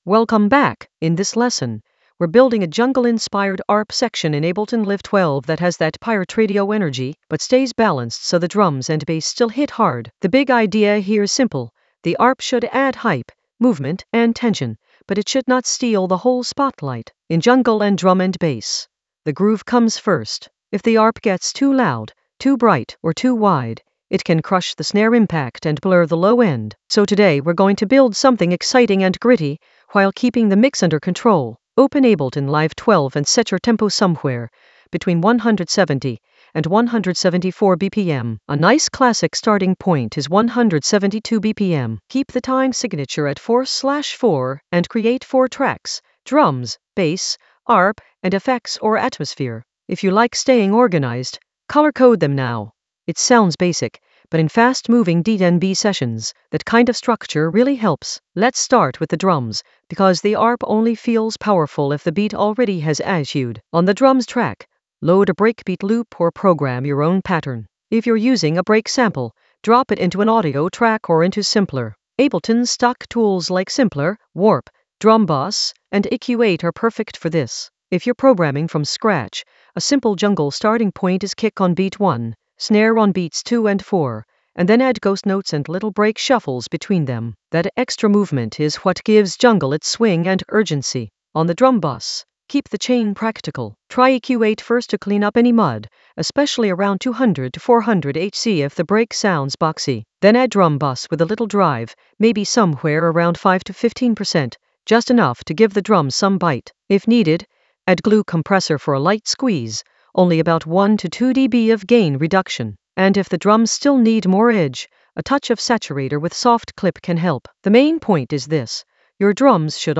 Narrated lesson audio
The voice track includes the tutorial plus extra teacher commentary.
An AI-generated beginner Ableton lesson focused on Jungle arp balance session for pirate-radio energy in Ableton Live 12 in the Arrangement area of drum and bass production.